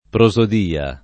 prosodia [ pro @ od & a ] s. f.